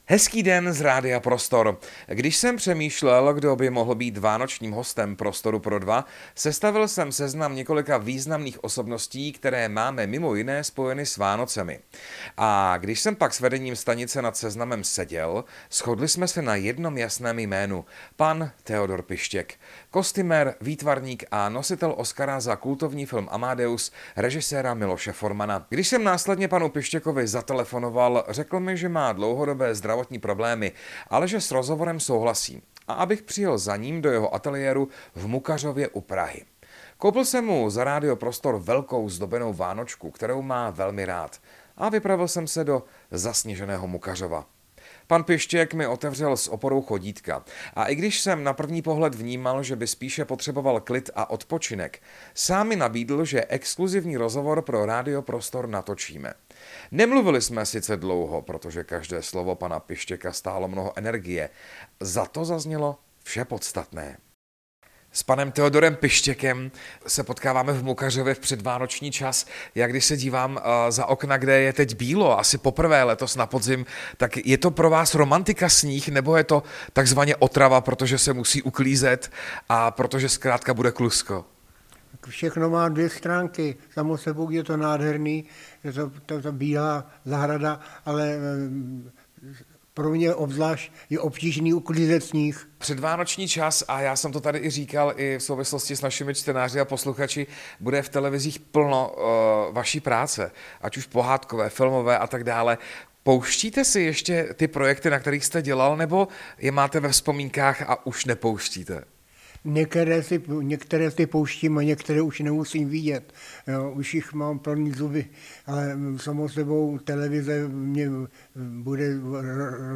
Radio Prostor natočilo exkluzivní předvánoční rozhovor se světoznámým kostýmním výtvarníkem a nositelem Oscara Theodorem Pištěkem.
Promluvil s ním nejen o tom, jak mu aktuálně je, ale také o svých aktuálních pracovních aktivitách. Při otázce na politickou situaci doma i ve světě se pak dokázal i na chvíli rozčílit.